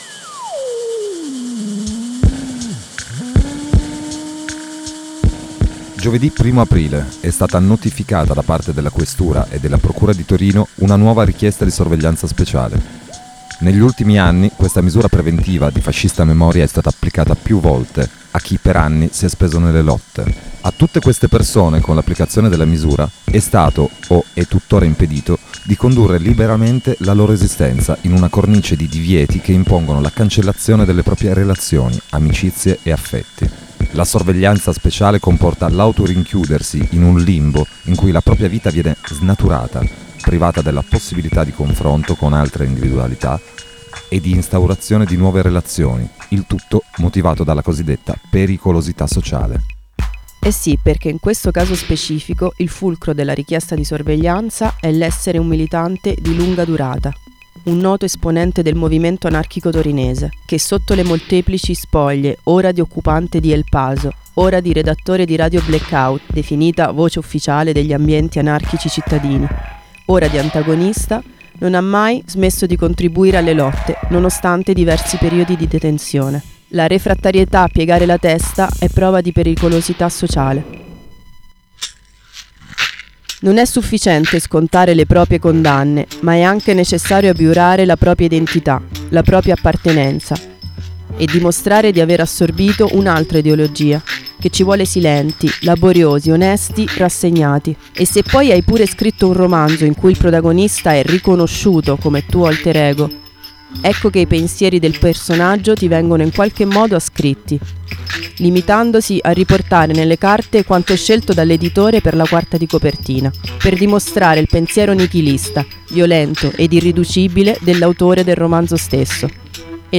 a seguire l’intervista